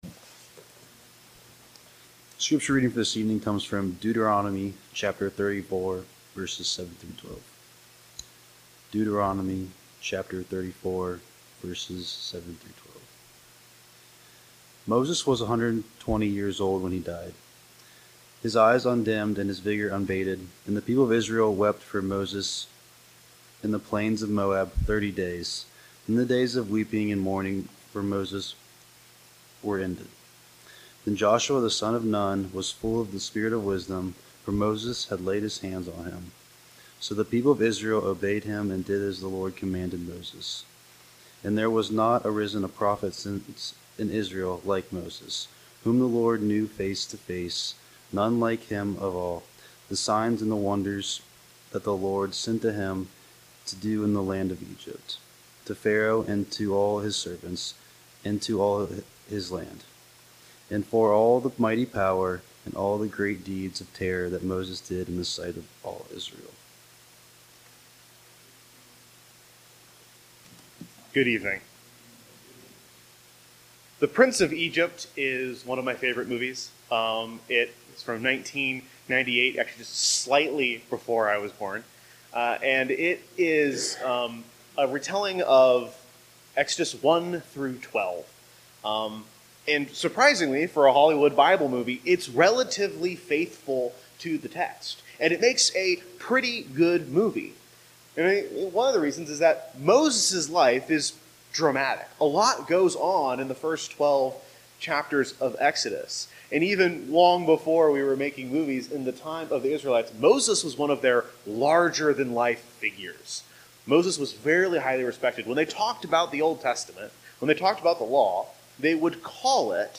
Passage: Deuteronomy 34:7-12 Service: Sunday Evening Topics